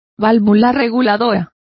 Complete with pronunciation of the translation of throttle.